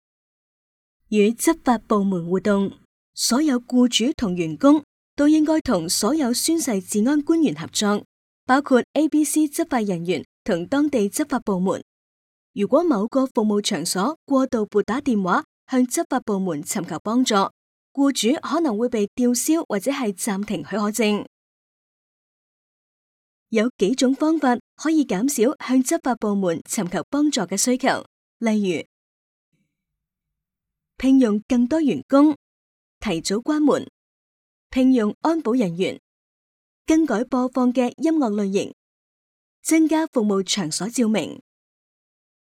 Cantonese_Female_003VoiceArtist_10Hours_High_Quality_Voice_Dataset